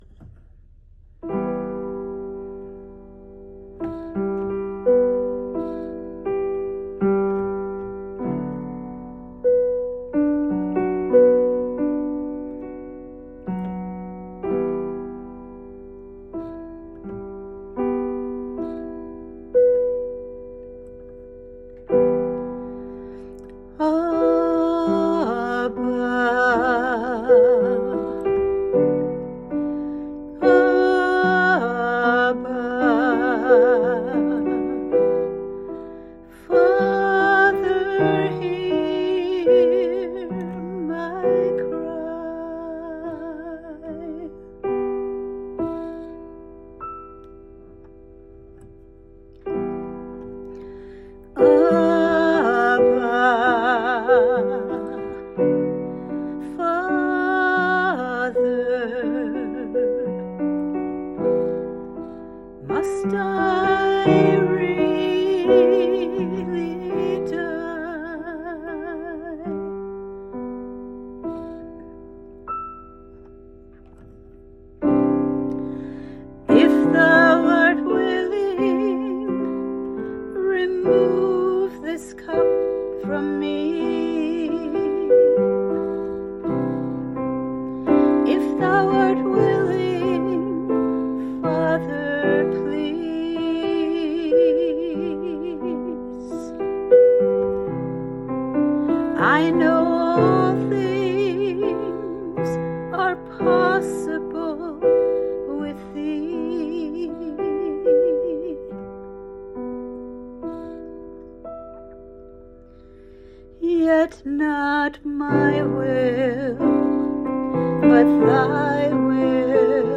meditative song